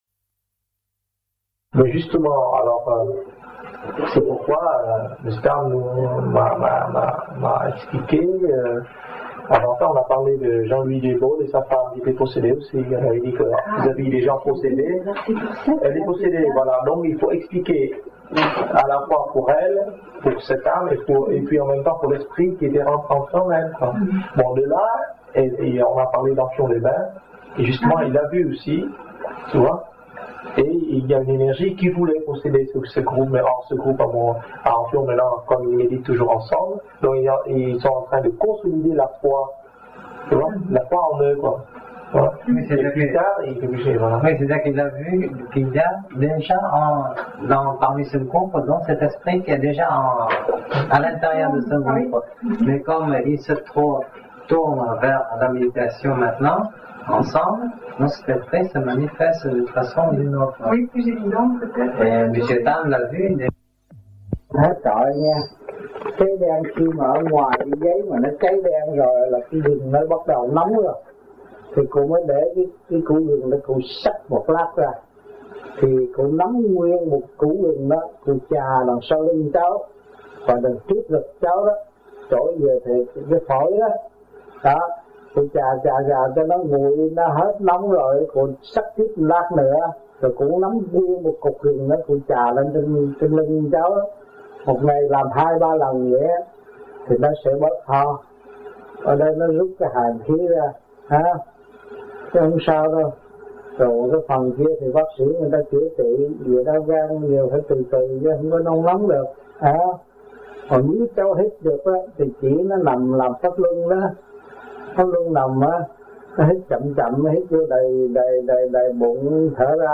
1980 Đàm Đạo